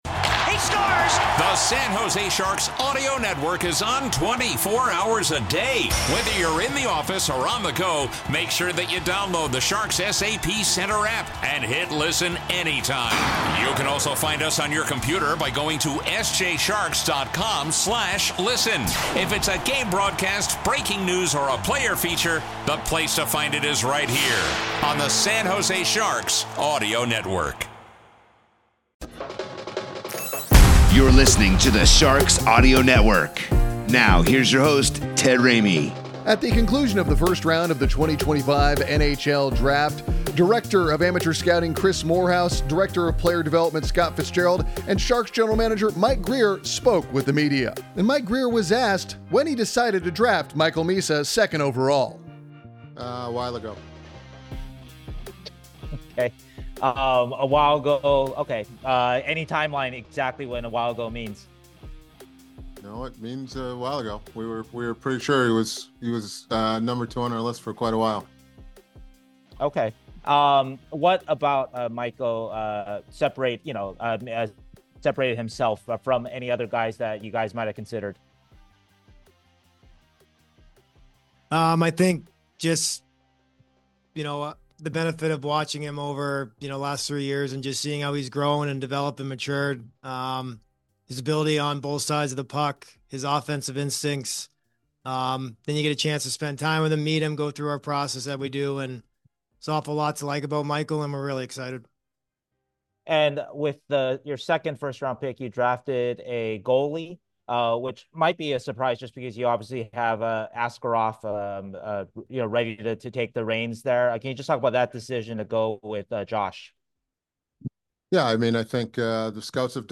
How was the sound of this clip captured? Spoke with the media after the first round of the 2025 NHL Draft.